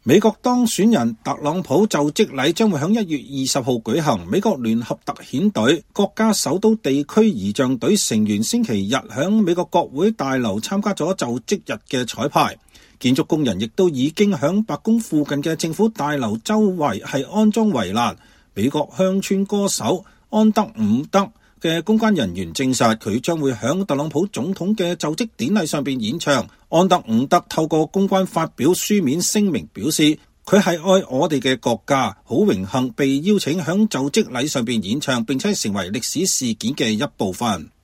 美國總統當選人唐納德·特朗普(Donald Trump)就職典禮將於1月20日舉行。美國聯合特遣部隊-國家首都地區儀仗隊成員週日(1月12日)在美國國會大樓參加就職日彩排。